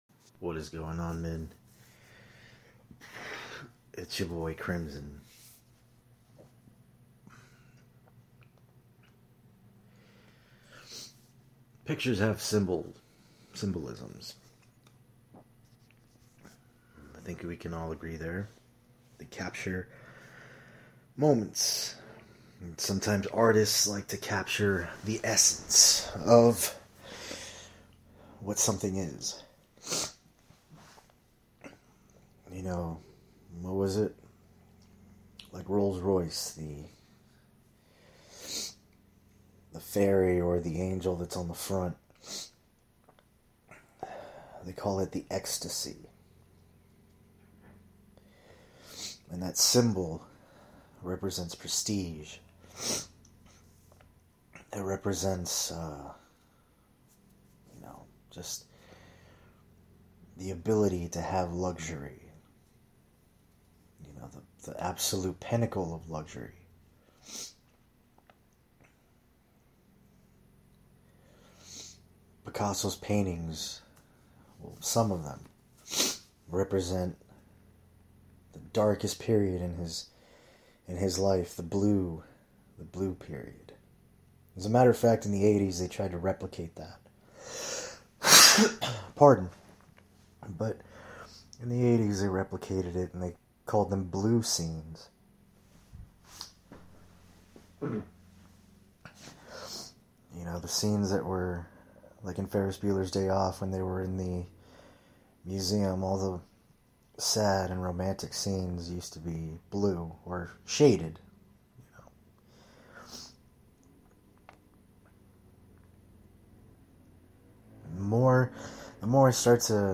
Sorry for the coughing and nose correcting.